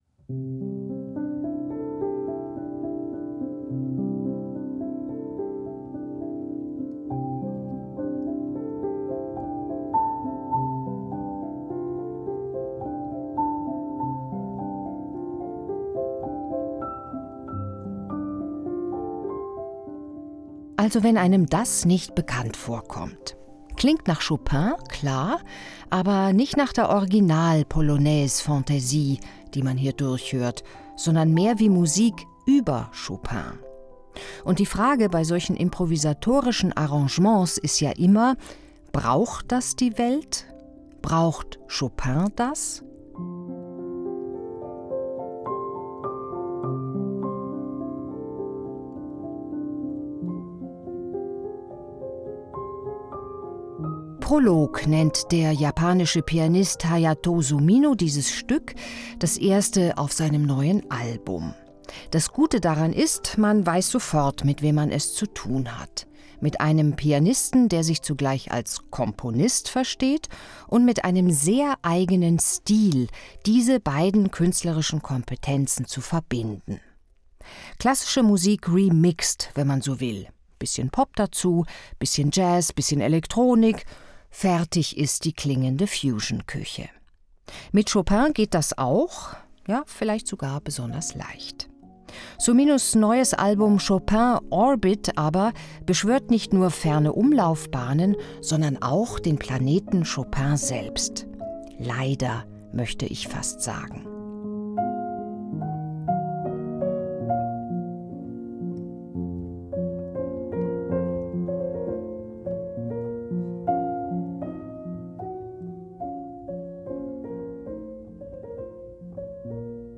Album-Kritik